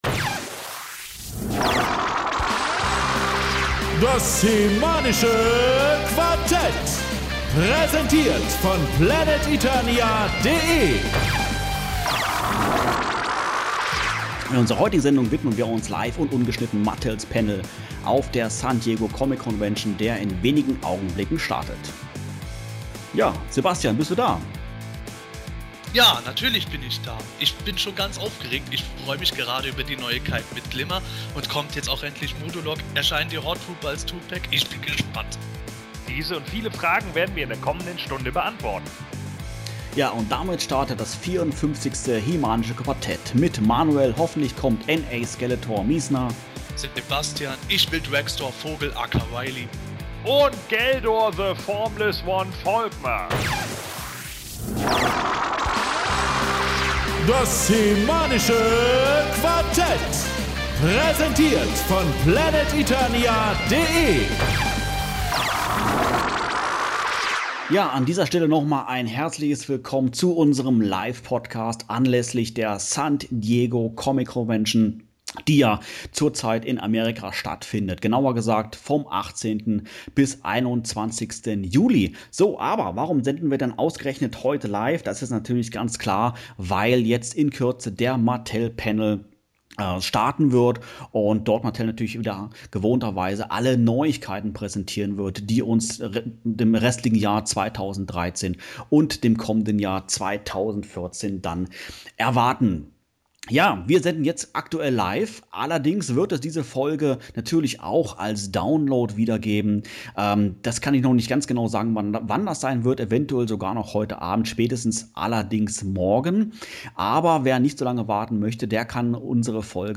Das HE-MANische Quartett hat zeitgleich über alle Entwicklungen und Enthüllungen des Panels live berichtet und selbstverständlich gibt es den Mitschnitt davon nun als Download zum immer wieder anhören. Zu hören war diese Live-Ausgabe am 19.07. zwischen 20 und 21:15 Uhr auf PlanetEternias "SDCC-Radio".